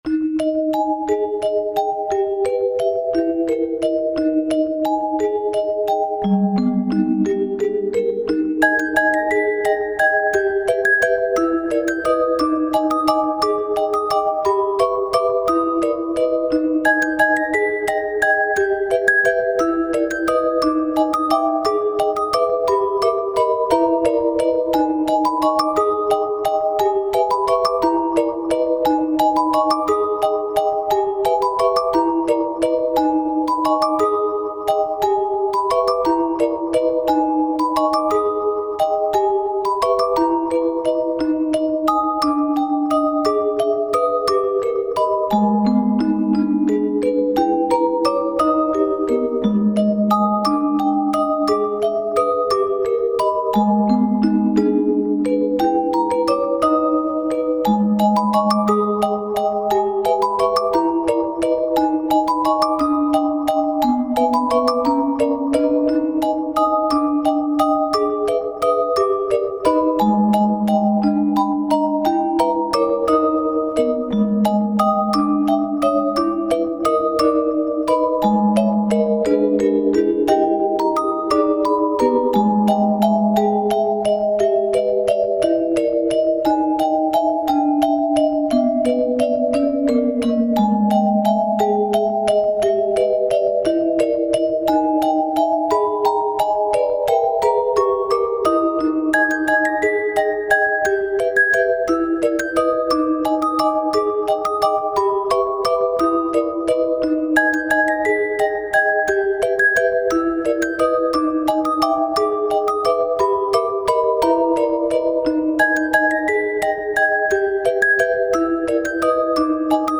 エフェクト強めなチェレスタによる三拍子の楽曲で、縦と横のリズムの切り替えで展開を作ることを意識している。